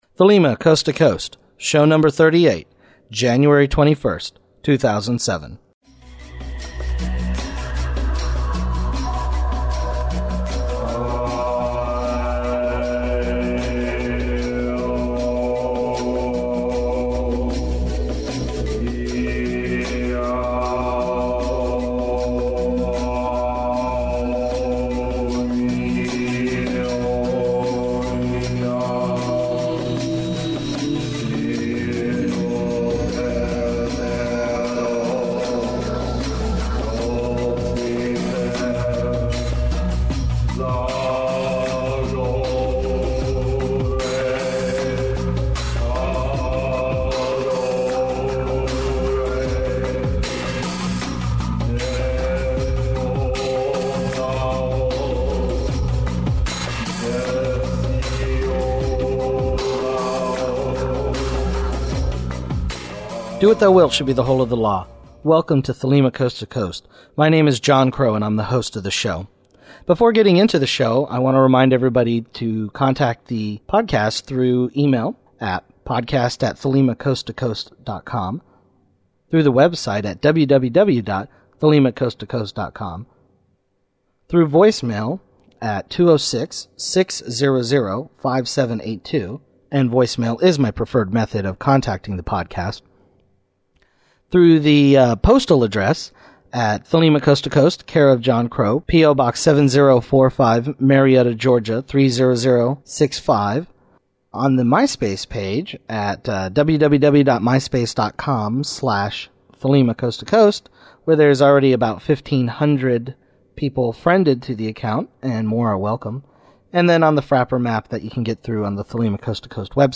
Listener feedback
Follow-up Interview